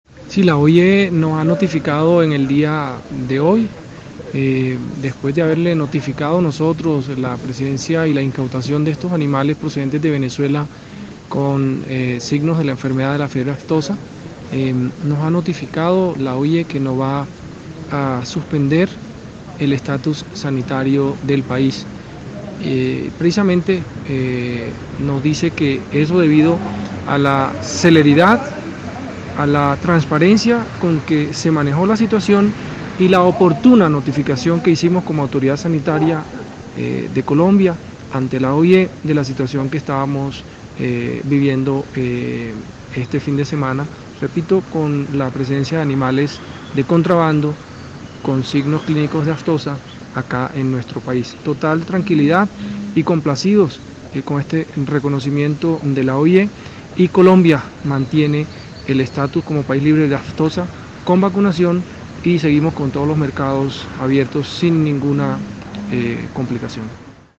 • Descargue la declaración del gerente general del ICA :